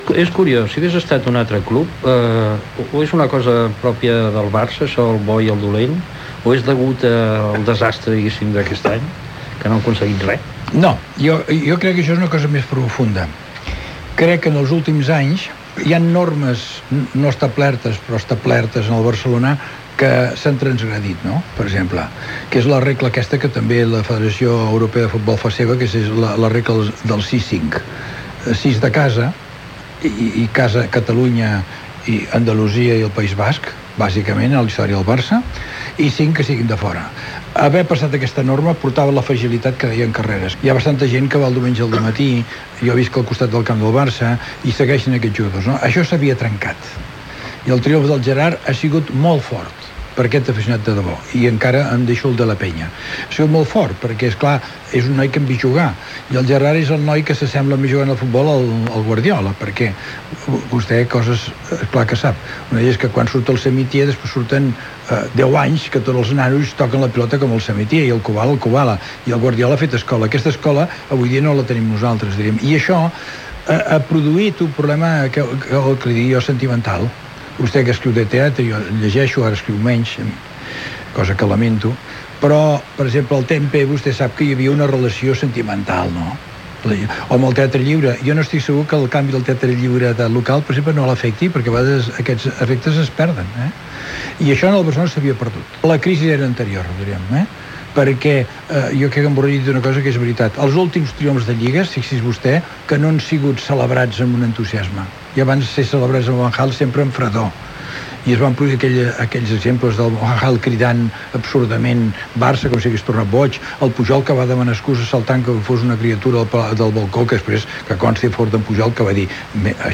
Secció del programa "Saló de fumadors" amb el convidat Ernest Lluch.
Info-entreteniment